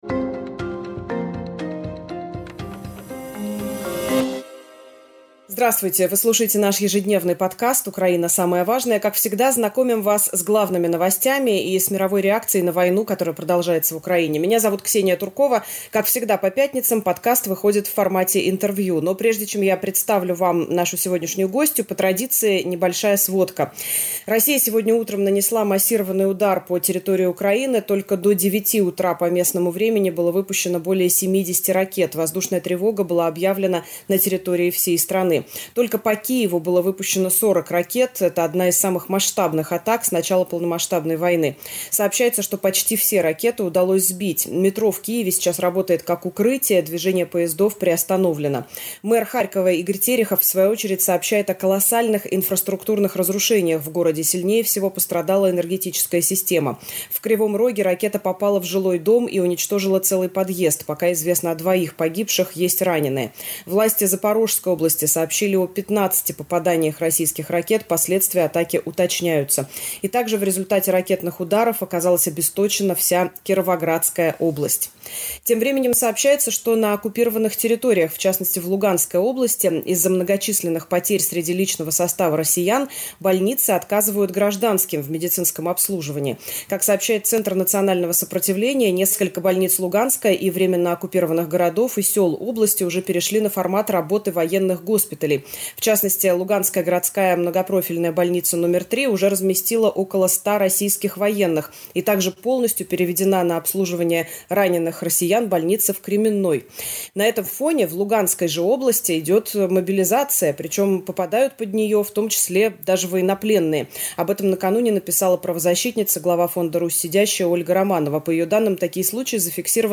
Украина. Самое важное. Интервью с Ольгой Романовой
В этом выпуске ведущая Ксения Туркова беседует с главой фонда «Русь Сидящая» Ольгой Романовой. В оккупированной Луганской области на войну отправляют военнопленных | В российских тюрьмах продолжается массовая вербовка заключенных